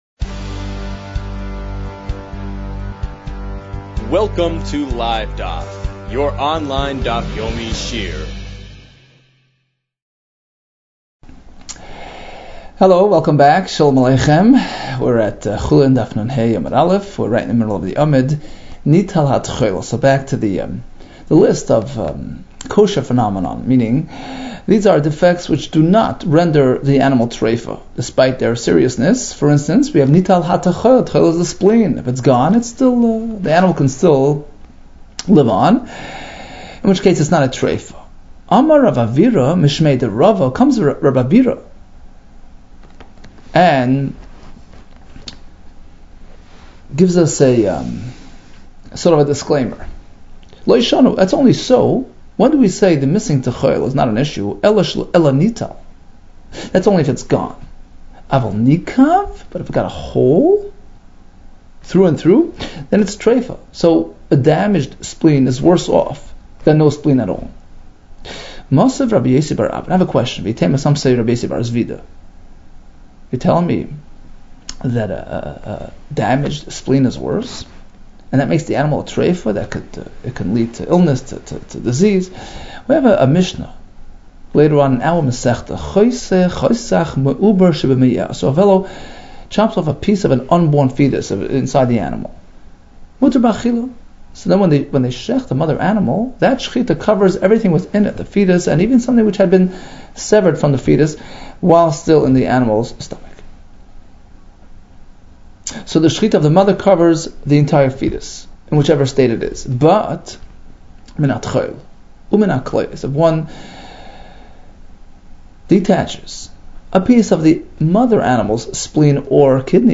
Chulin 55 - חולין נה | Daf Yomi Online Shiur | Livedaf